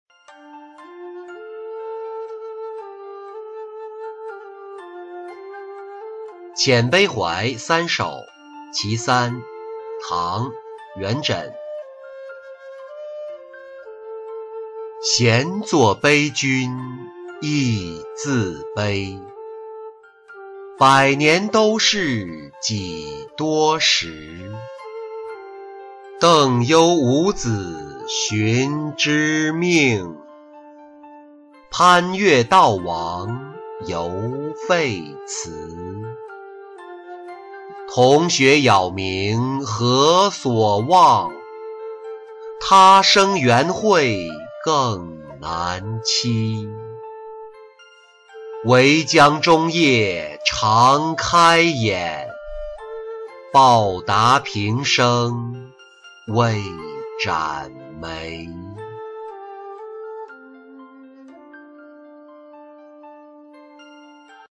李白墓-音频朗读